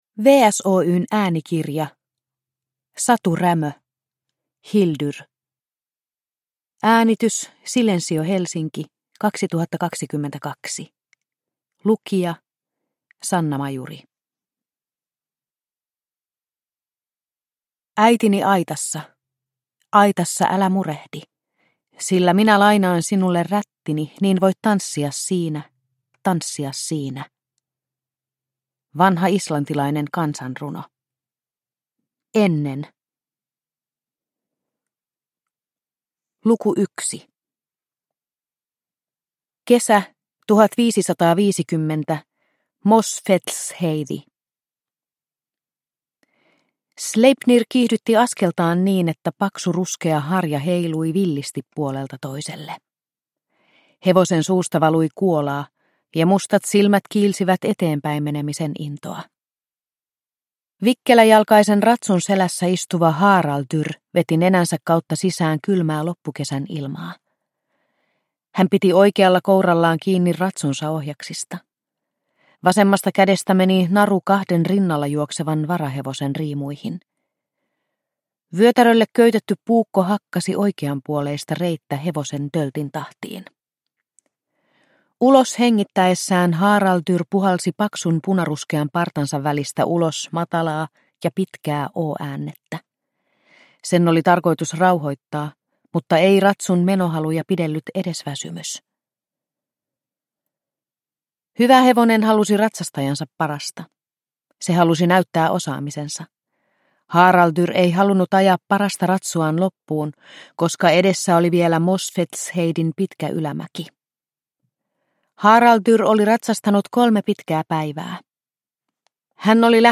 Hildur – Ljudbok – Laddas ner
Uppläsare: